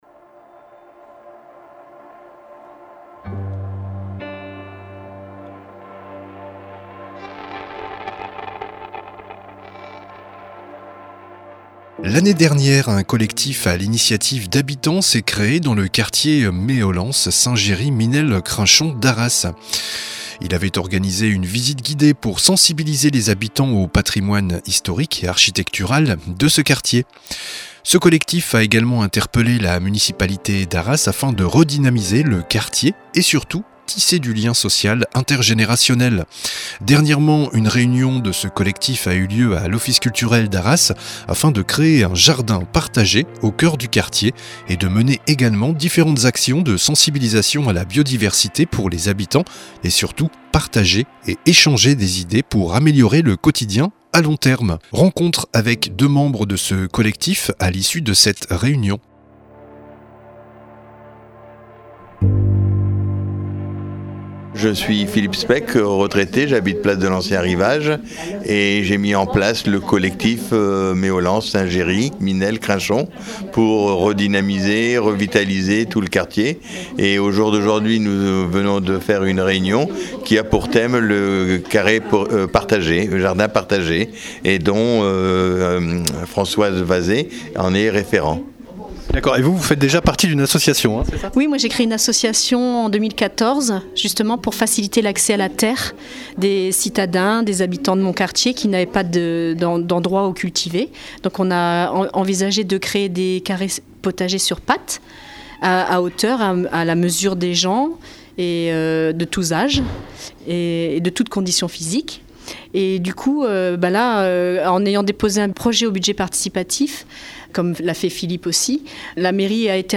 Entretien avec 2 membres du collectif citoyen Meaulens autour de la création d’un jardin partagé au cœur du quartier Meaulens/St-Géry/Minelle/Crinchon à l’issue d’une réunion qui s’est déroulée à l’Office Culturel d’Arras.